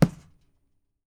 PUNCH F   -S.WAV